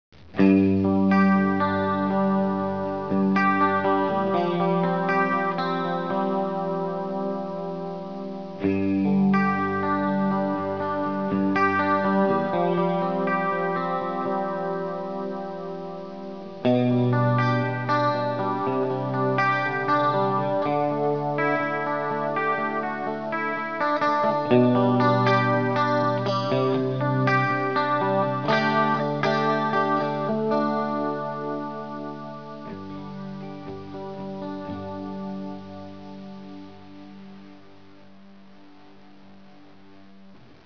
ESEMPIO AUDIO 01 -CHORUS
Chorus Tonex
01-CHORUS-2.wav